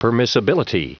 Prononciation du mot permissibility en anglais (fichier audio)
permissibility.wav